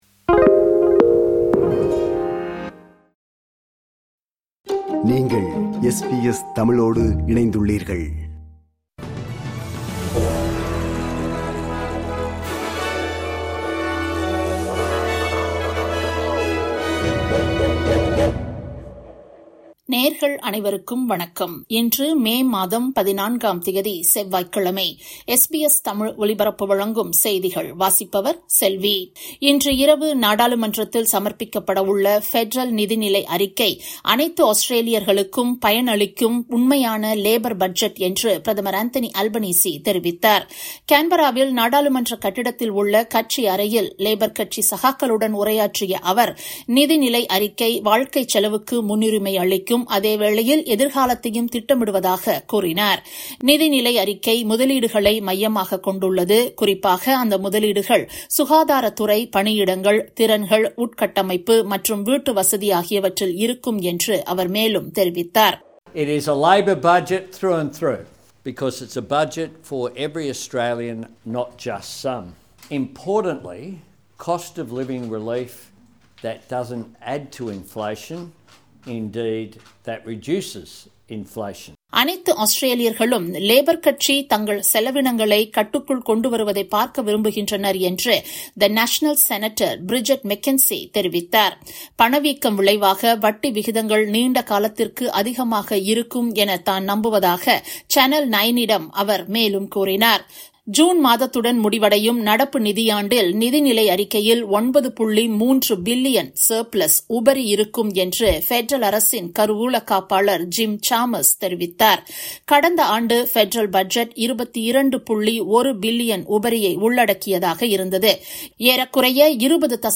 SBS தமிழ் ஒலிபரப்பின் இன்றைய (செவ்வாய்க்கிழமை 14/05/2024) செய்திகள்.